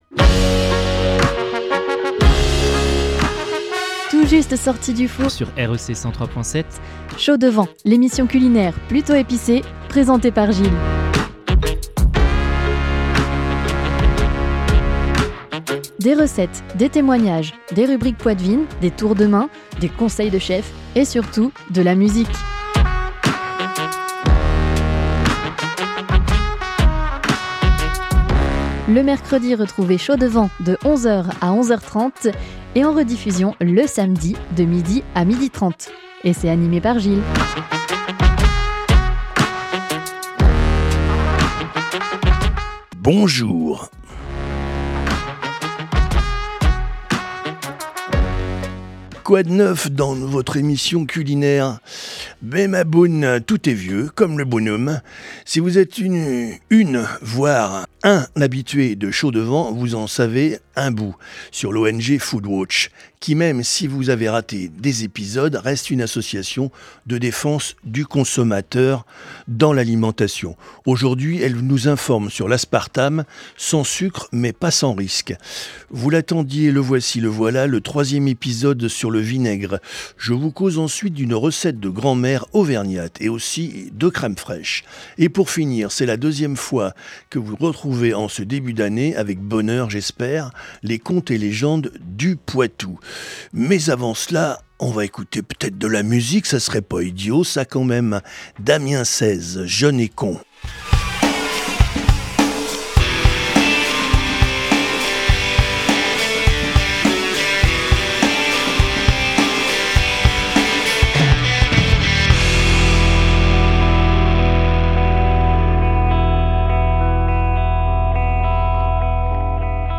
avec anecdotes, témoignages , rubriques , recettes , conseils de chef et musiques !